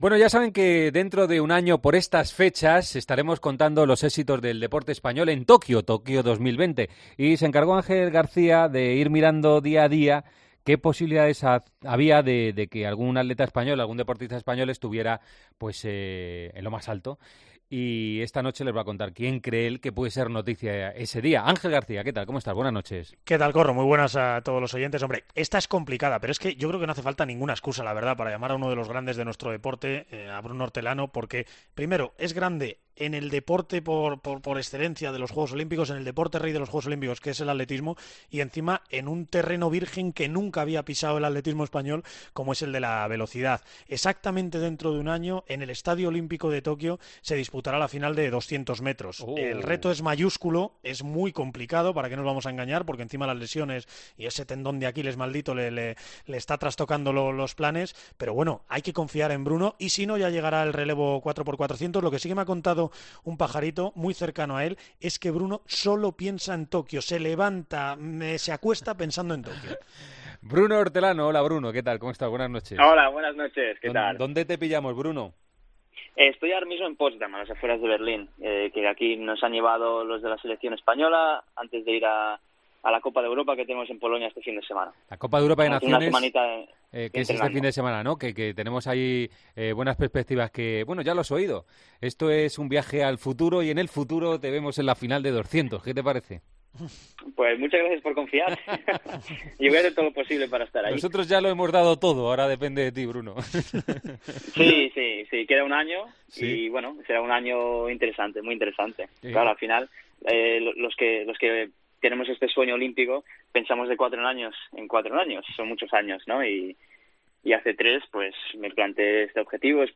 El atleta español Bruno Hortelano pasó este lunes por los micrófonos de ‘El Partidazo de COPE ’ para analizar lo que puede llegar a ser su actuación en los próximos Juegos Olímpicos de Tokio y sus planes deportivos más cercanos, con los ojos puestos en el próximo Mundial que se disputará el próximo mes de octubre en Doha .